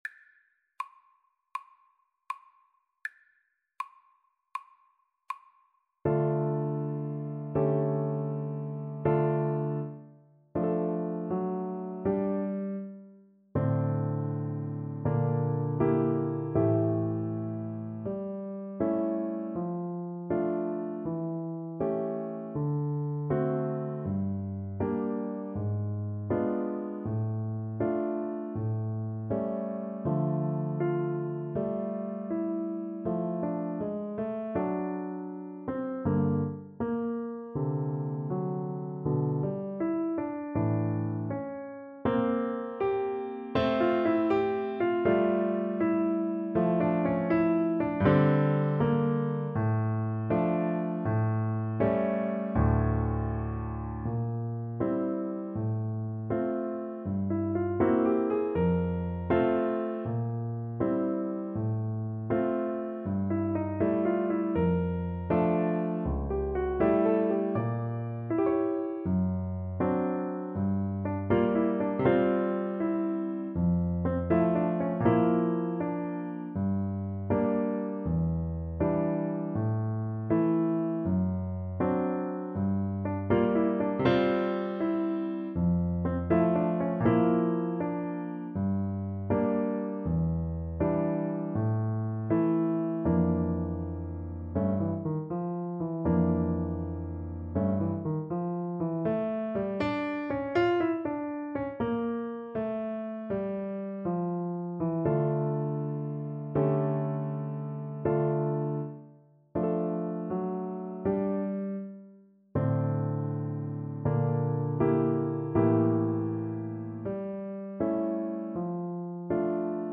Play (or use space bar on your keyboard) Pause Music Playalong - Piano Accompaniment Playalong Band Accompaniment not yet available reset tempo print settings full screen
D minor (Sounding Pitch) E minor (Tenor Saxophone in Bb) (View more D minor Music for Tenor Saxophone )
Andante doloroso e molto cantabile
Classical (View more Classical Tenor Saxophone Music)